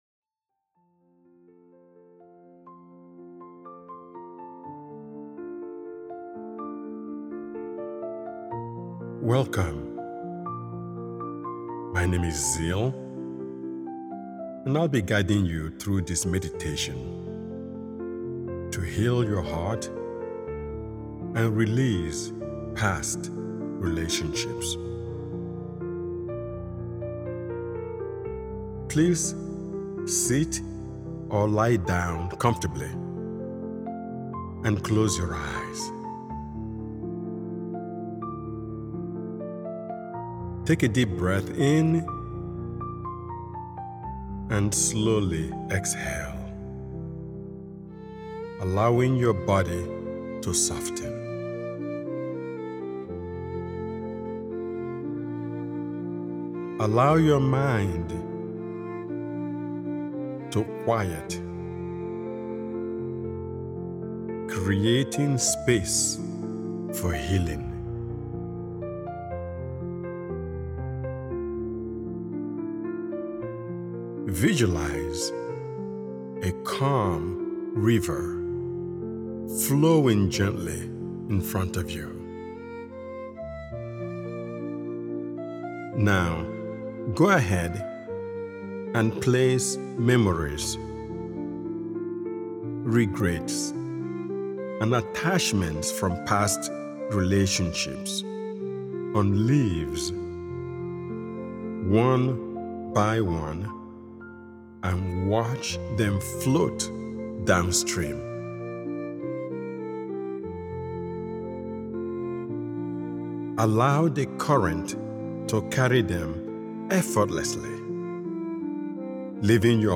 This meditation begins with slow, mindful breathing, helping you: